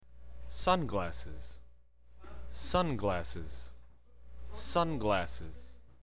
Take a listen and practice saying the word in English.